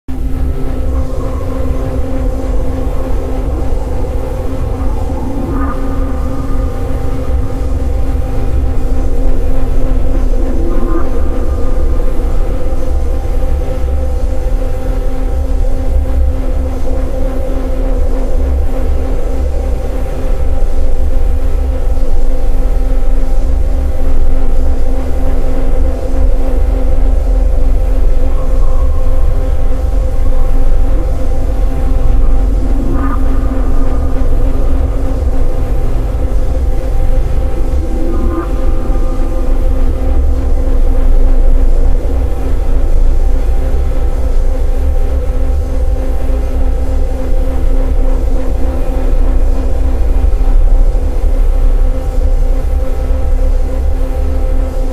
140bpm electronic edm ambient harsh dark